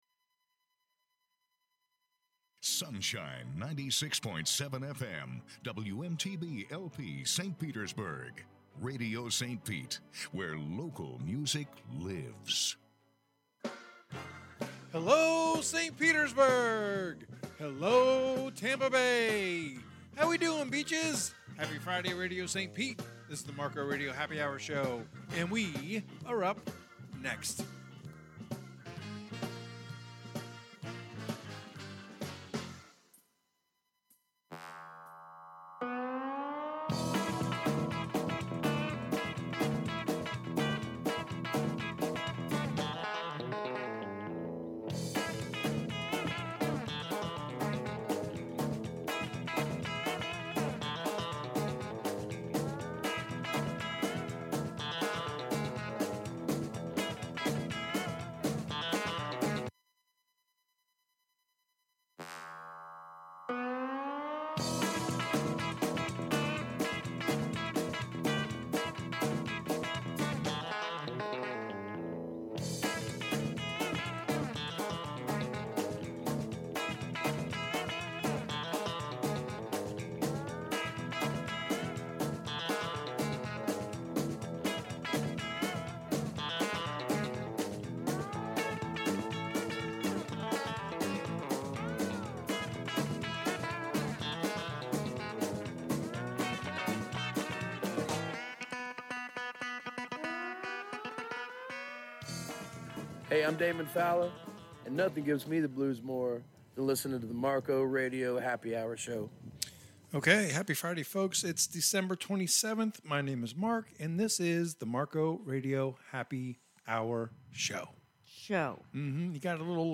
Airs live Fridays 6-8 pm ET